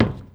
Footstep_Metal 02.wav